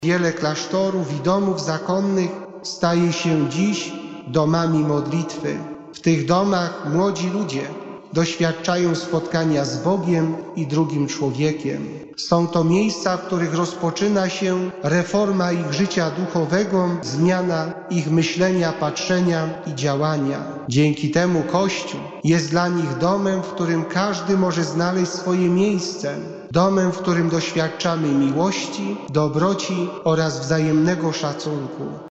W stołecznej bazylice św. Krzyża modlono się w intencji osób poświęconych Bogu.